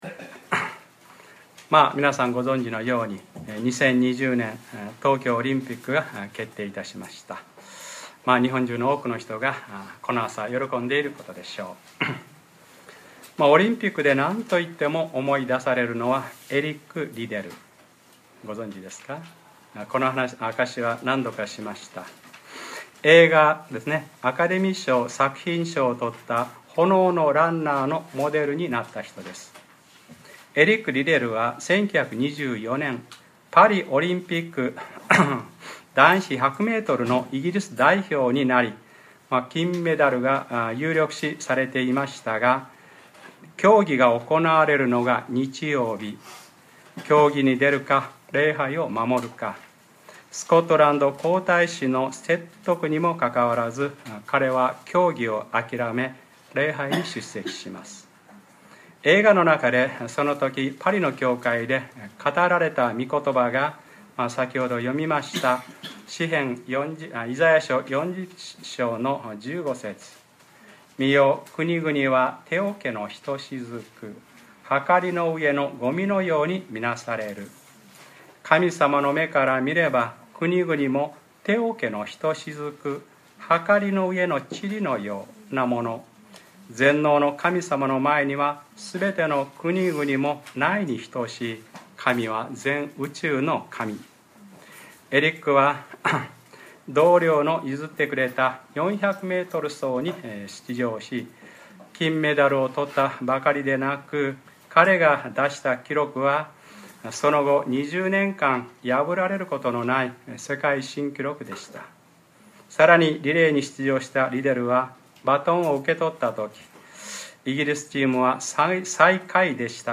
2013年9月8日(日）礼拝説教 『ルカｰ２３ 彼女はよけい愛したからです』